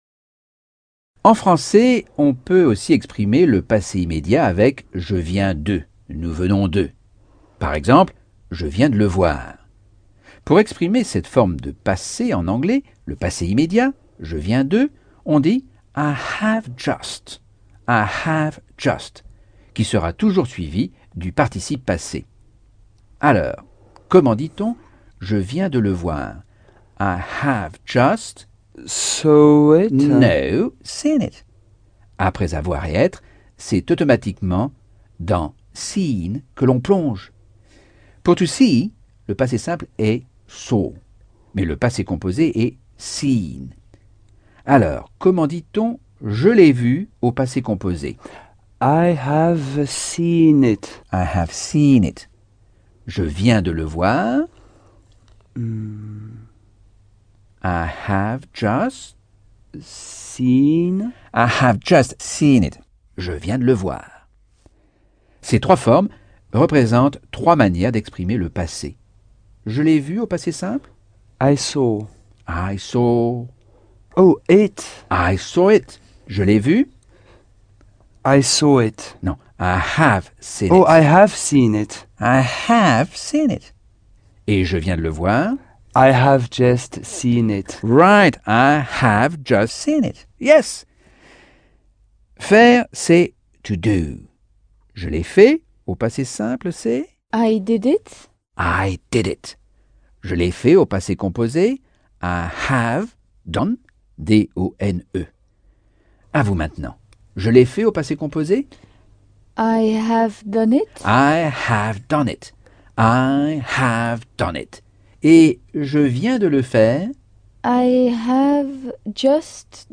Leçon 1 - Cours audio Anglais par Michel Thomas - Chapitre 9